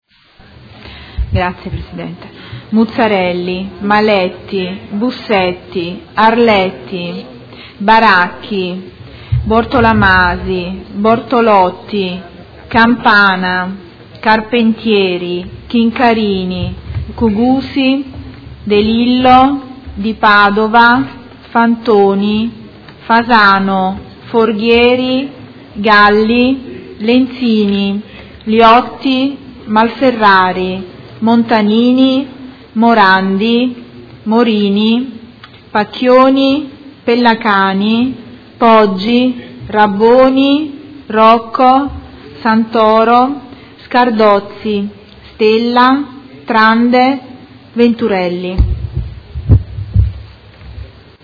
Seduta del 10/12/2015. Appello
Segretario Generale